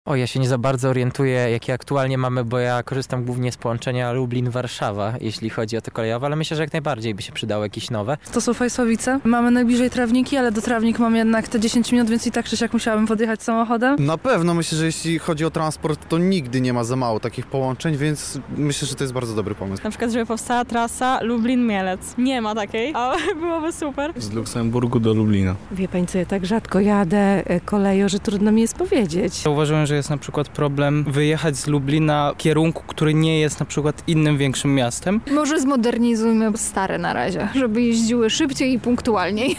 Zapytaliśmy przechodniów, czy ich zdaniem powinny powstać nowe połączenia w lubelskiej kolei i jakich tras jeszcze brakuje:
SONDA